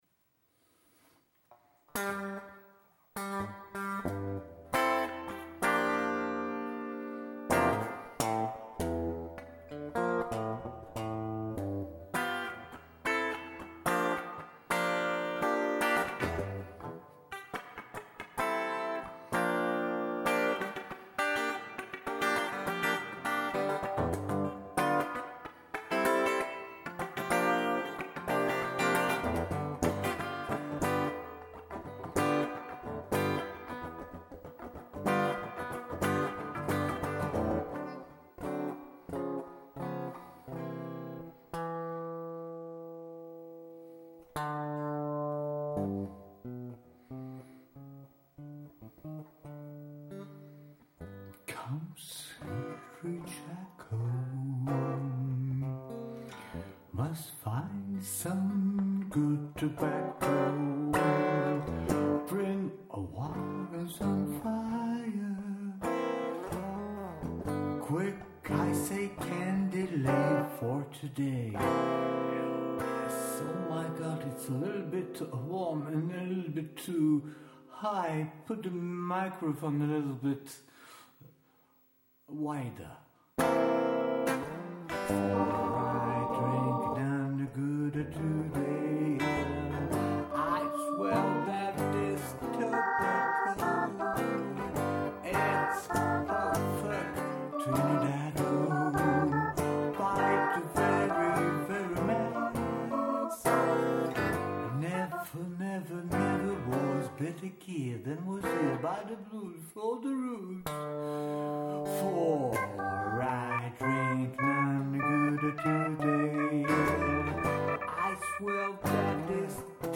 La première partie de la chanson vous démontre que c’est vraiment « live », l’orgue B3 est ajouté en « dubbing ».